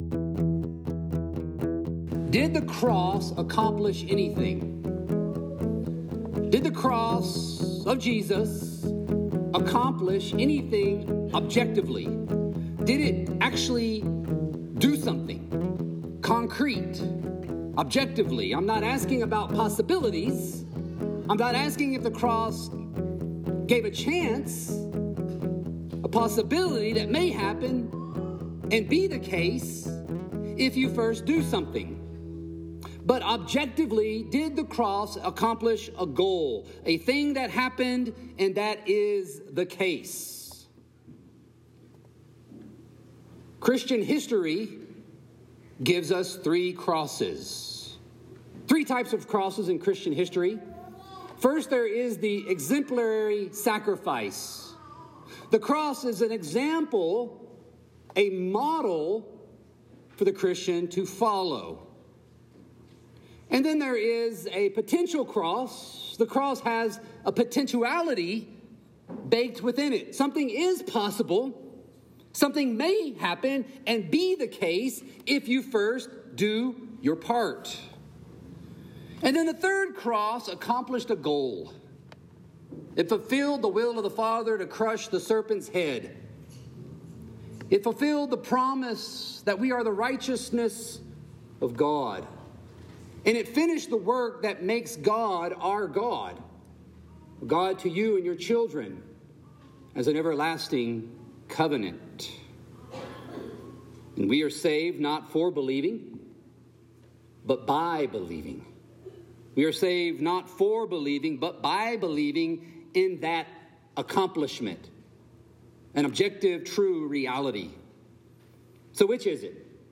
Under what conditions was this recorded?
Sermons from Covenant Reformed Church: Missoula, MT